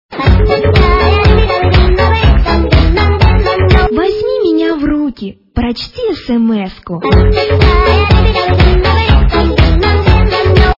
» Звуки » звуки для СМС » Мелодия финская полька
качество понижено и присутствуют гудки